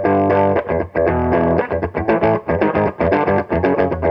RIFF7.wav